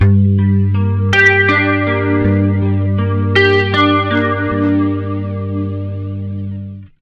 80MINARP G-L.wav